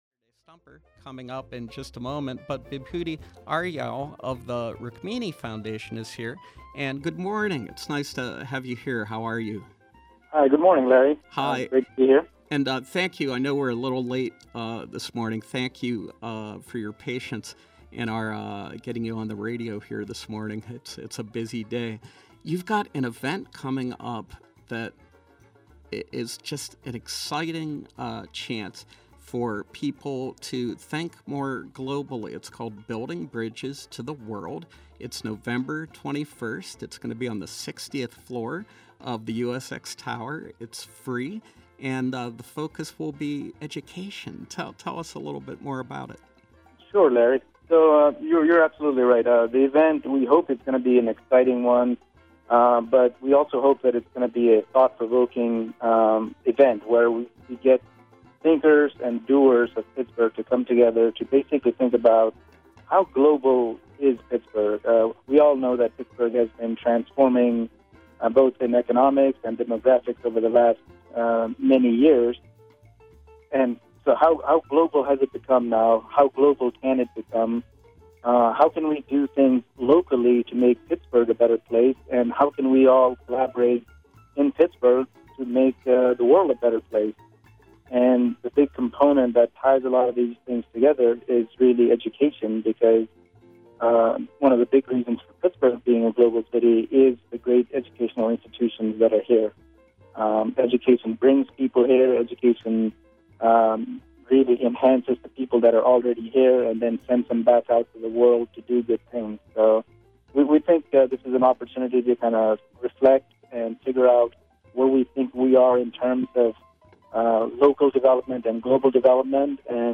Home » Callers, Featured, Interviews